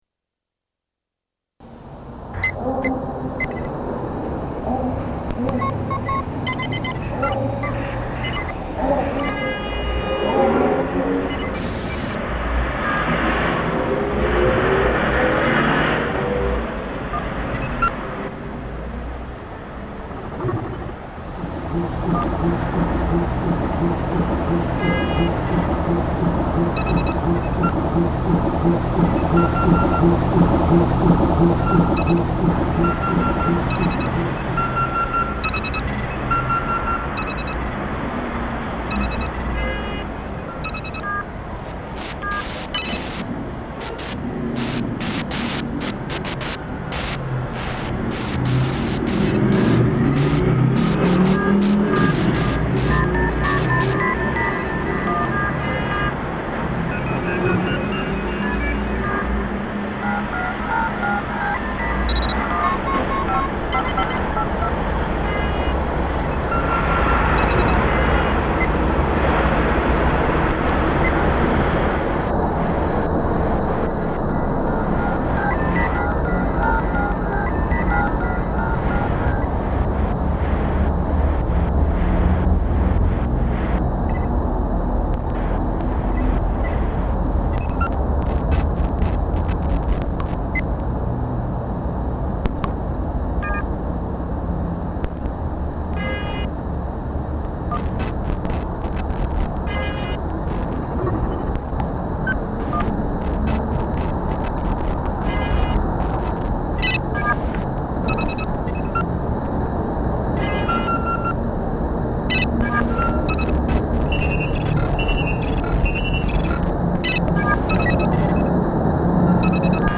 música concreta y electrónica
En ella se utilizan grabaciones de campo, tratadas y modificadas con medios electrónicos, para crear una composición que re-organiza el ruido y busca crear nuevas texturas musicales a partir de sonidos, en teoría, ‘no agradables’ para el oído humano.